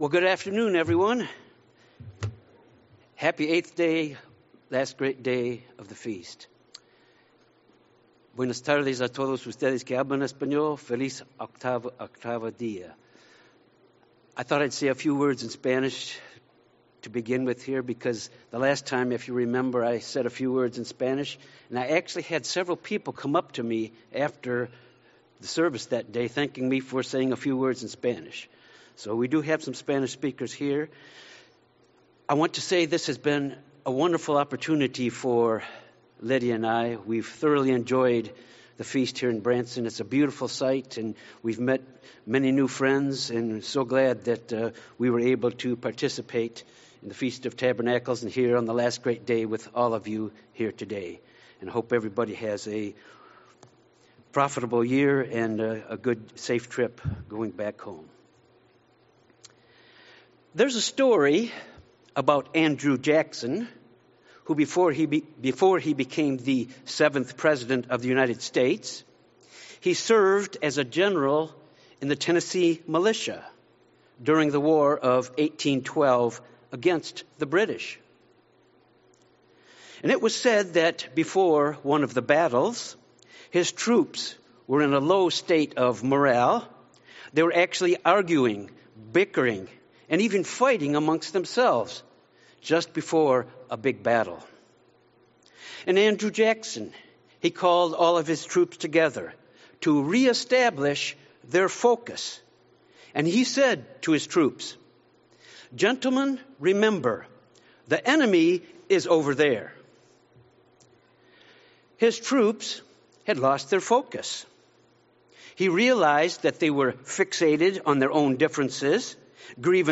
This sermon was given at the Branson, Missouri 2022 Feast site.